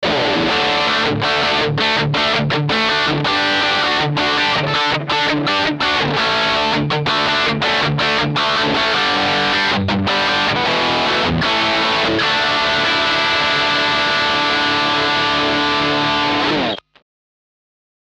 Verwendet man das MXR EVH 5150 Overdrive für Rhythmus Sounds hat es einen unheimlich fetten obertonreichen Klang.
Dabei komprimiert es den Sound sehr stark, das ist jedoch genau was man vom Van Halen Sound erwartet.
Singende Soli mit einem großartigen Punch, die sich im Bandgefüge bestens durchsetzen, kann das Pedal wahnsinnig gut.
Crunchige Riffs und singende Leads mit unendlichen Gain-Reserven.